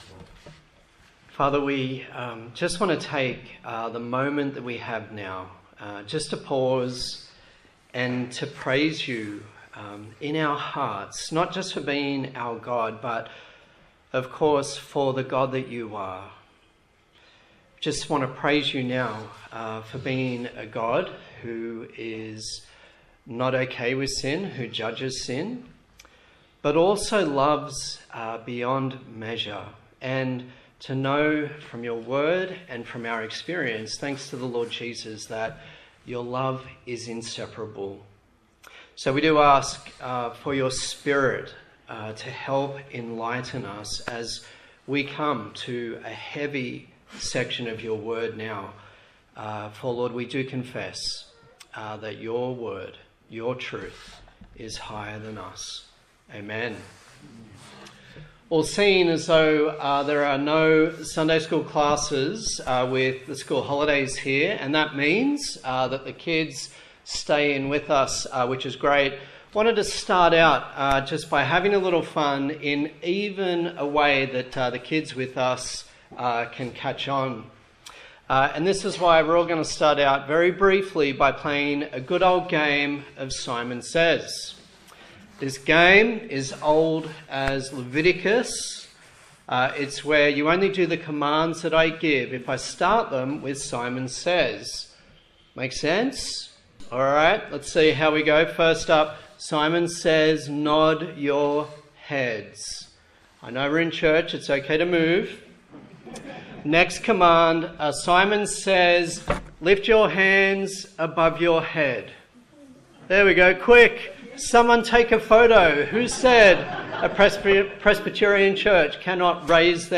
Leviticus Passage: Leviticus 26 Service Type: Sunday Morning